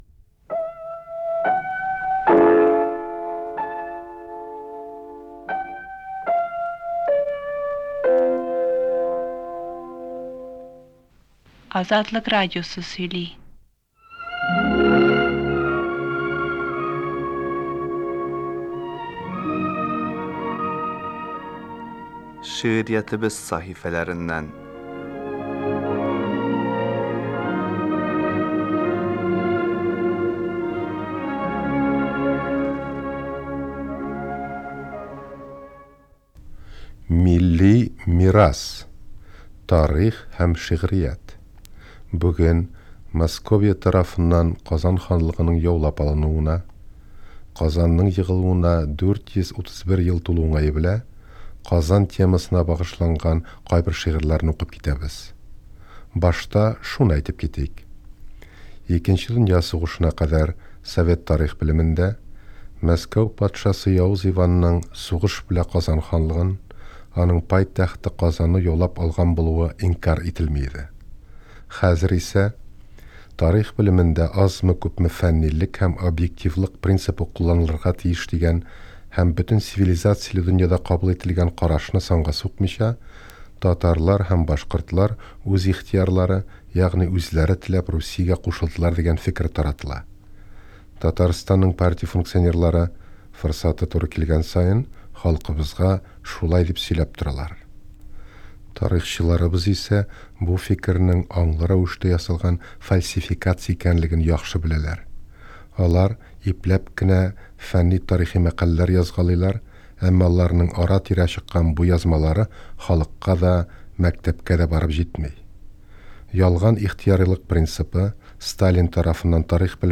Совет чорында татарлар Казан ханлыгын яулап алу вакыйгалары турында тарафсыз мәгълүматны бары тик Азатлык радиосыннан гына ишетә алган. 1983 елда чыккан бу тапшыруда Азатлык хәбәрчеләре Казанны яулап алу тарихын сөйли. Тапшыруда Казан турында шигырьләр һәм җырлар яңгырый.